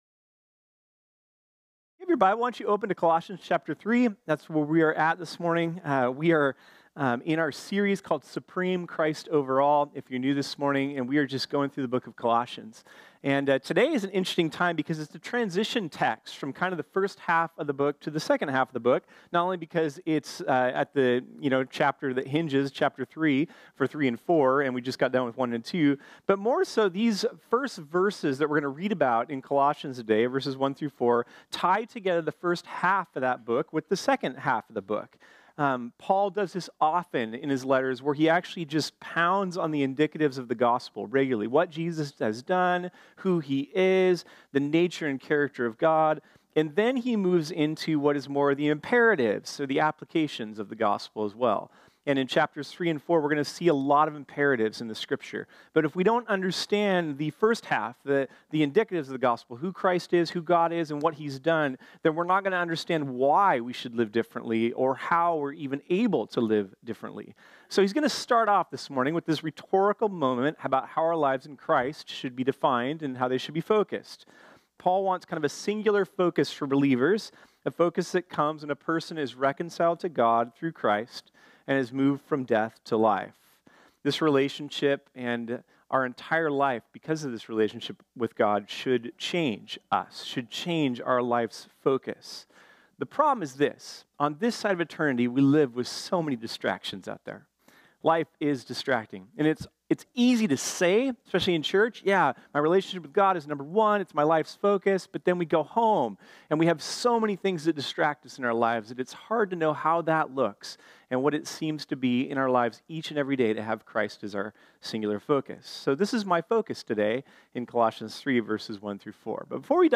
This sermon was originally preached on Sunday, October 28, 2018.